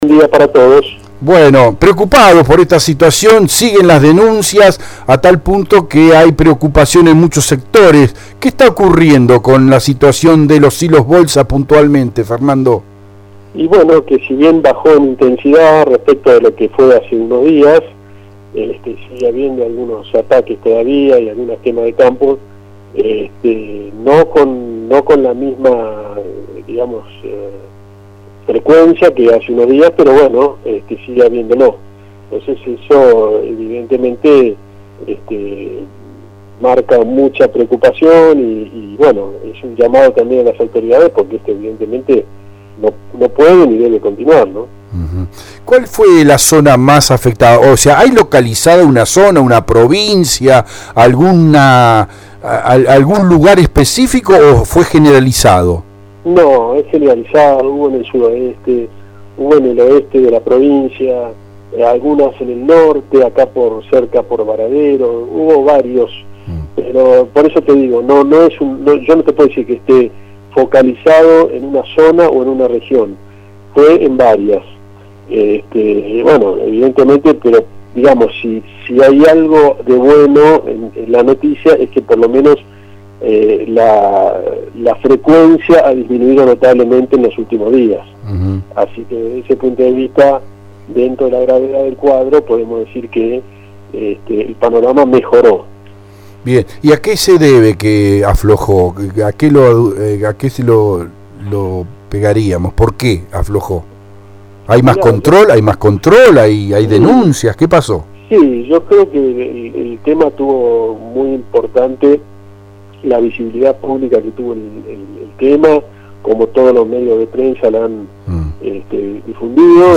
UNA TRISTE POSTAL DE MUCHOS CAMPOS EN LA PROVINCIA DE BUENOS AIRES.